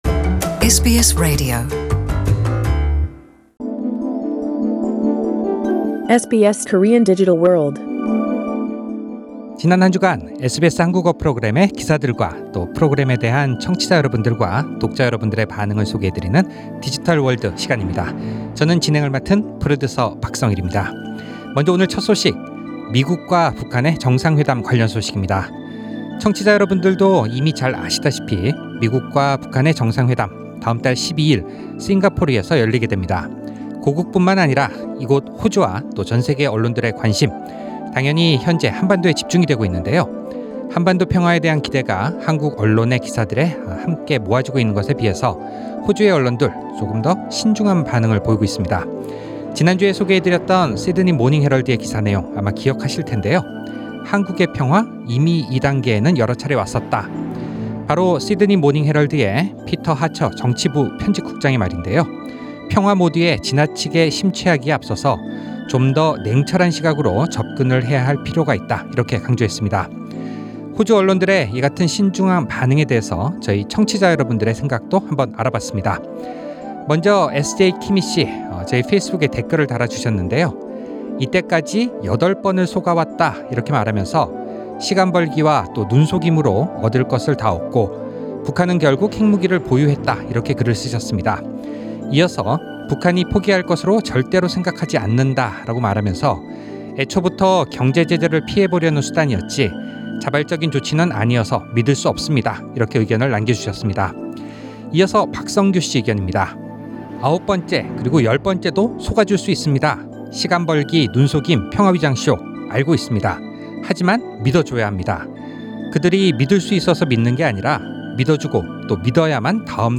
The Melbourne preliminary round for 2018 Changwon K-Pop World Festival was held at Deakin Edge at Federation Square on 11 May 2018.
Interview with performers Vocal competition Dancing competition Interview with Consul- General Share